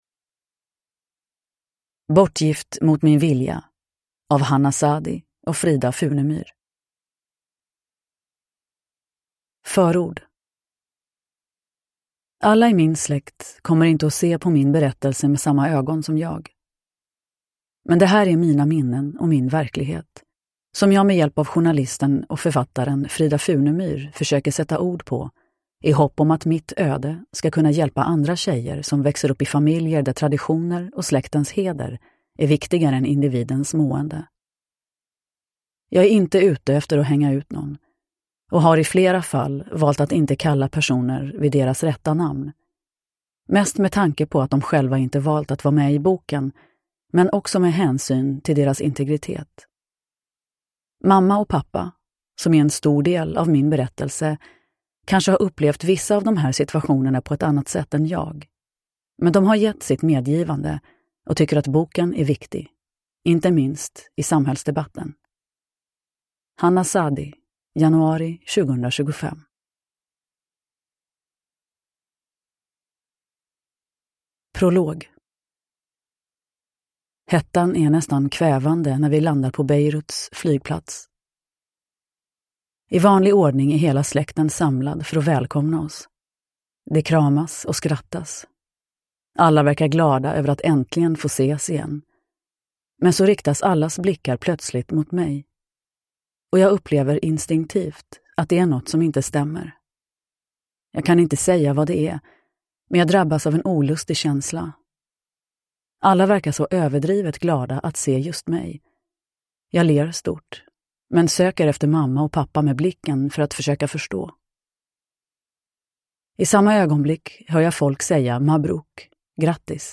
Bortgift mot min vilja – Ljudbok
Uppläsare: Nina Zanjani